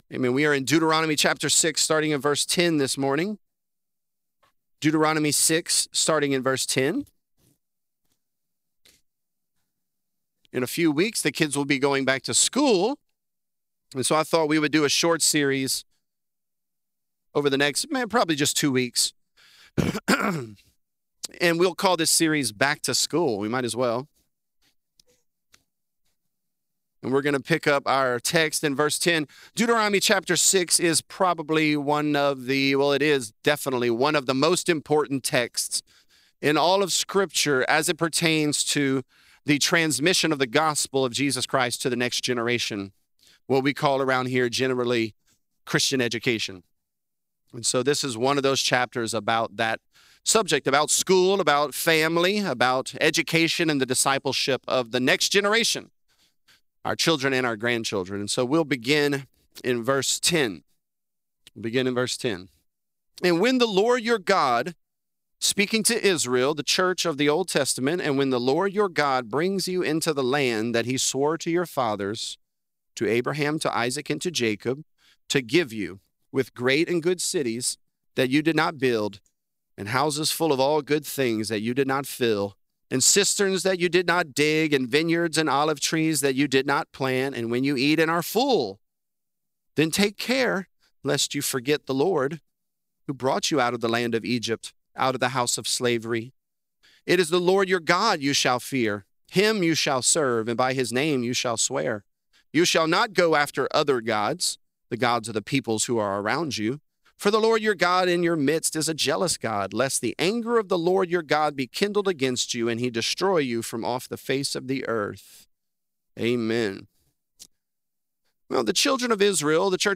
Multi-Generational Faithfulness | Lafayette - Sermon (Deuteronomy 6)